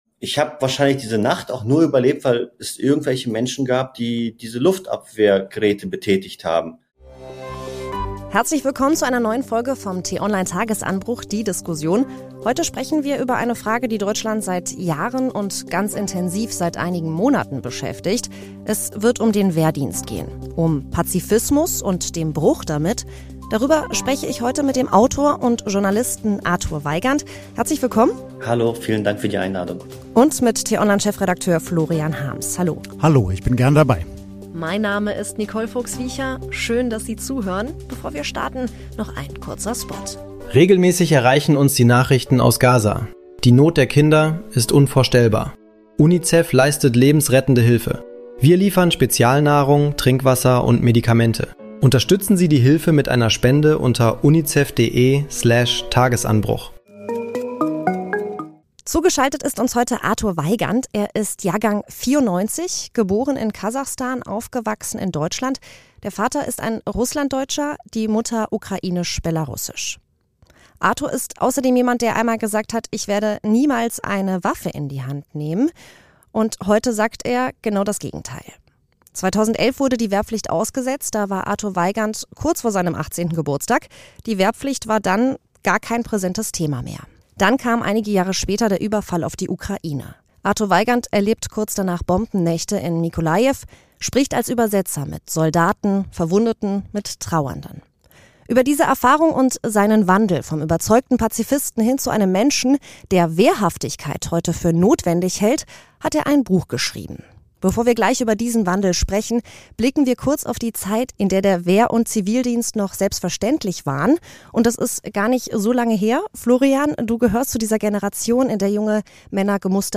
Tagesanbruch – die Diskussion Was bringt einen überzeugten Pazifisten dazu, zu sagen: So geht es nicht mehr?